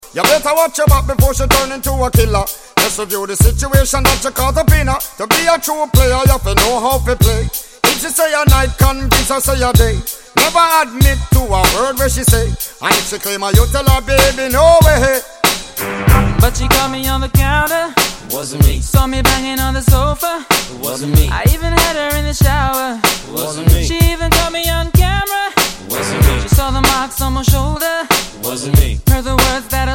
• Reggae